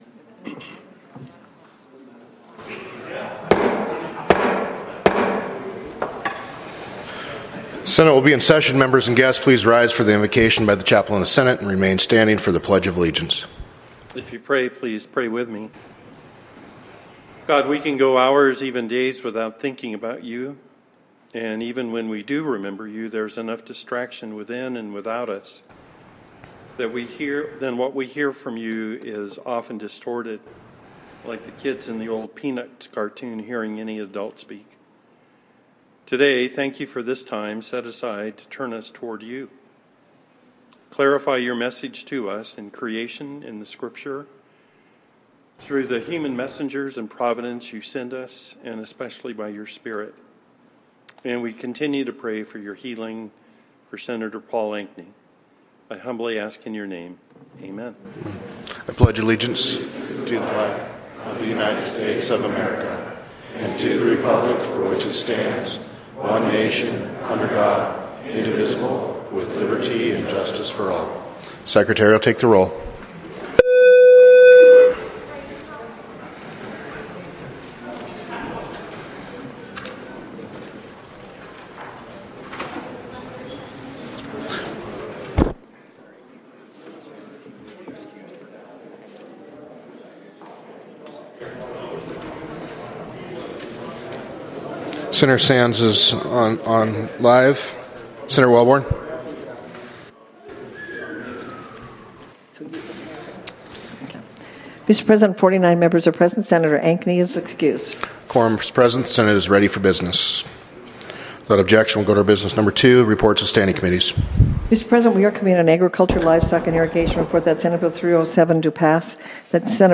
Senate Floor Session